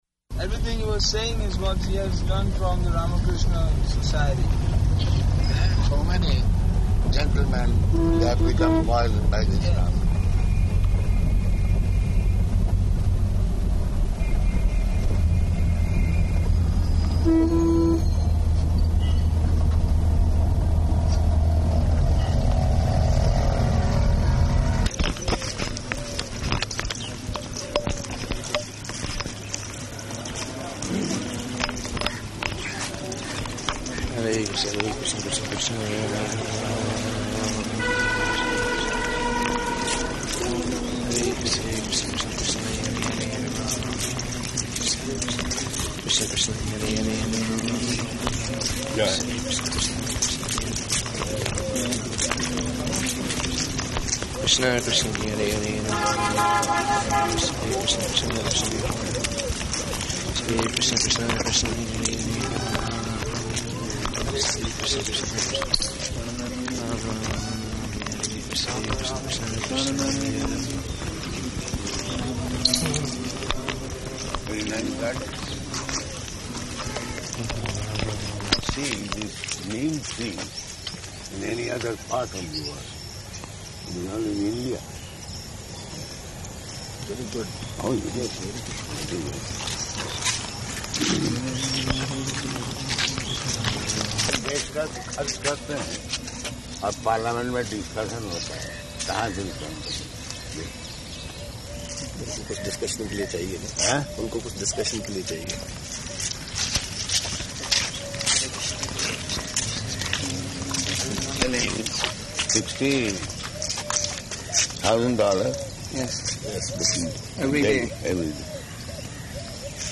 Morning Walk at Lodi Gardens
Type: Walk
Location: Delhi